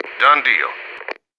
marine_order_complete4.wav